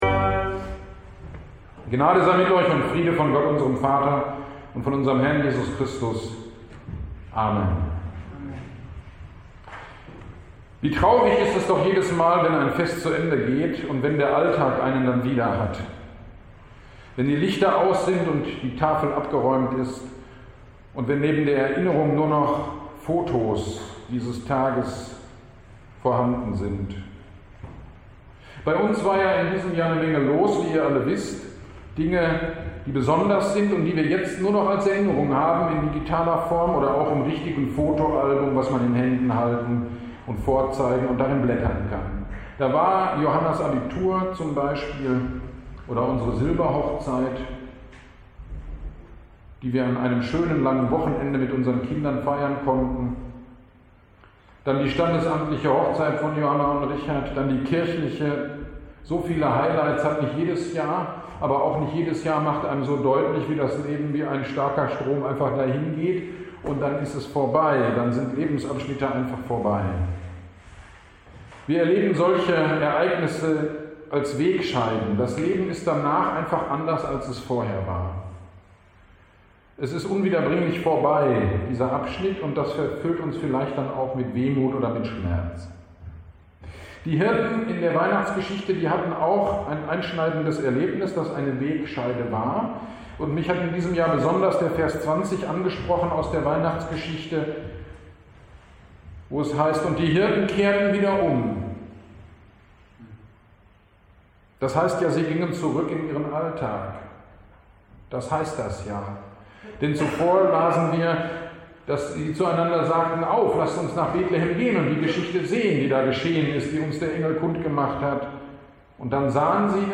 Silvesternacht 2021 - Predigt zu Lukas 2.20 - Kirchgemeinde Pölzig